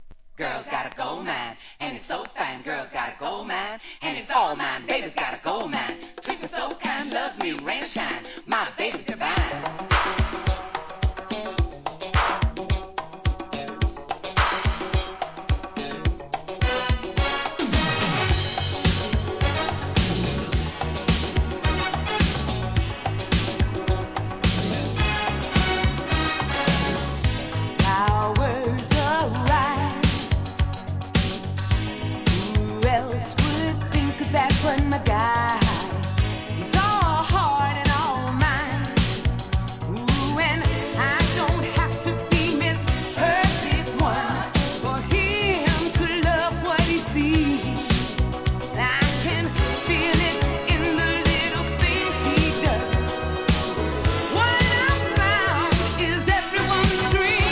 ¥ 440 税込 関連カテゴリ SOUL/FUNK/etc...
80's DISCOヒット‼